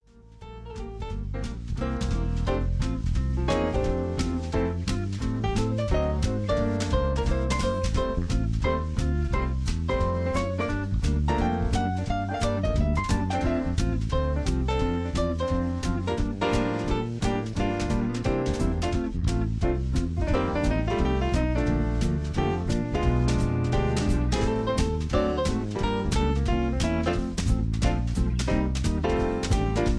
karaoke mp3 tracks